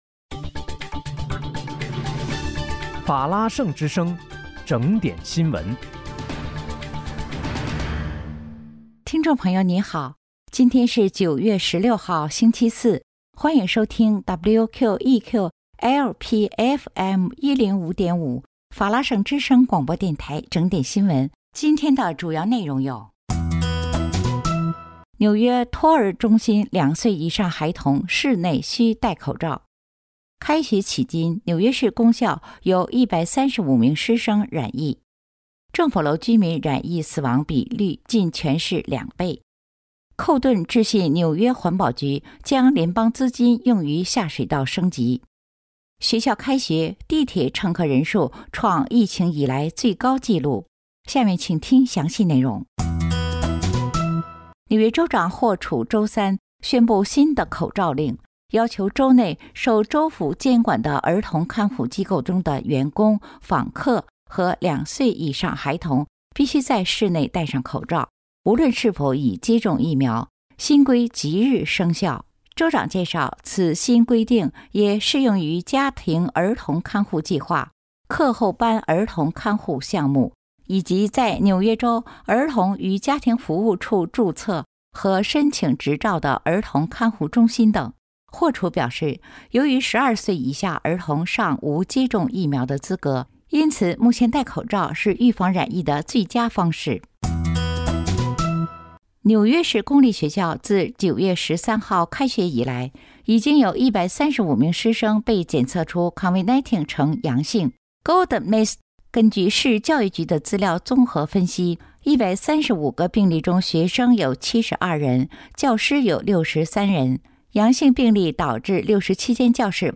9月16日（星期四）纽约整点新闻